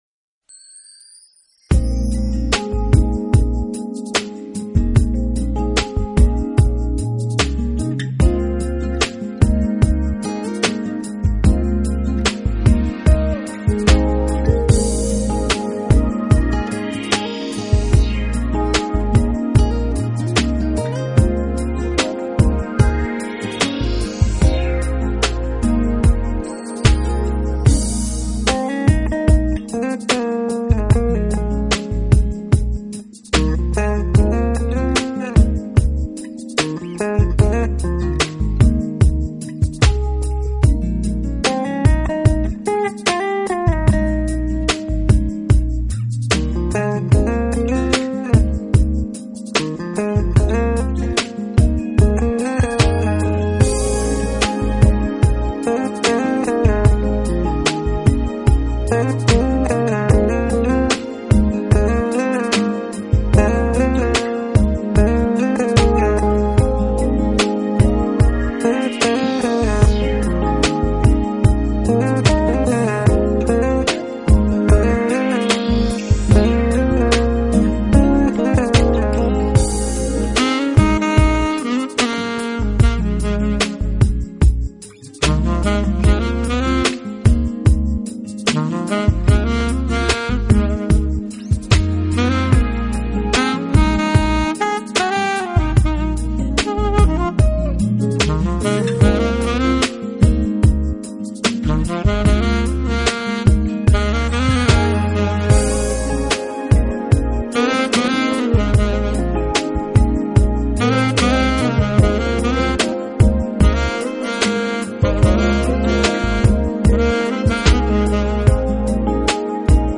风格：Smooth Jazz
比较放松。
Smooth Unban Jazz风格，乐器演奏及编曲注重营造轻松不失时尚的气氛，很适合休闲时欣赏。